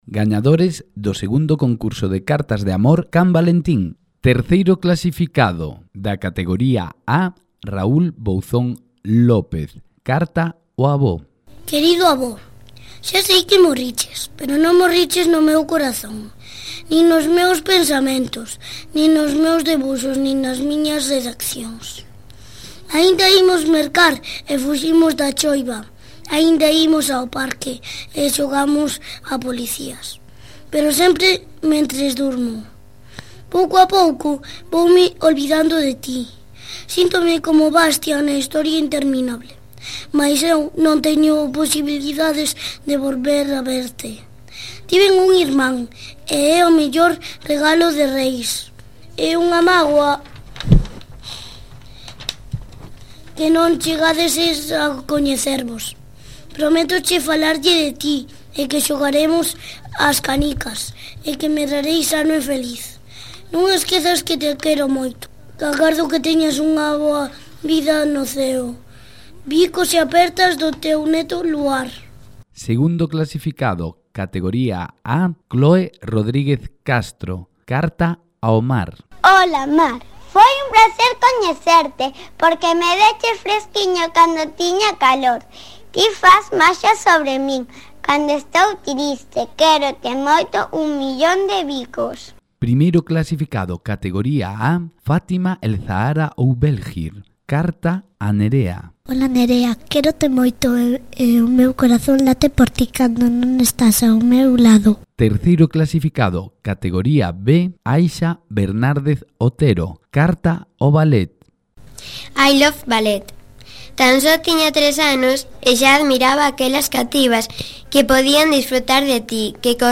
Escoita a entrevista PREMENDO AQUÍ Especial San Valentín 2018. Gañadores/as Concurso de Cartas de Amor "CAN VALENTÍN 2018" Escoita aos/as Gañadores/as lendo as súas cartas PREMENDO AQUÍ Especial San Valentín 2017.